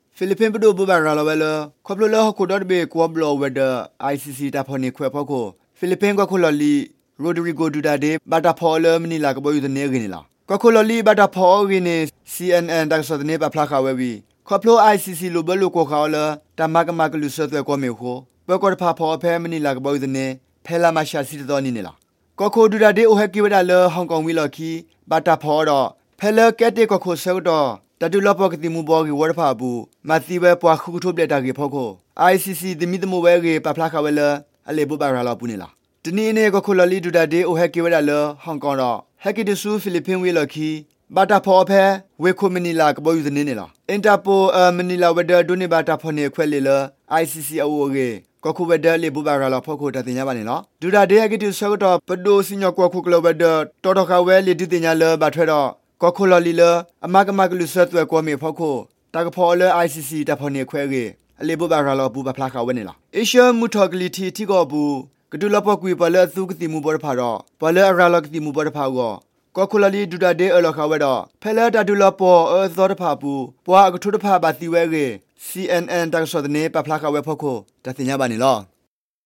Radio တၢ်ကစီၣ် တၢ်ကစီၣ် ထံဂုၤကီၫ်ဂၤ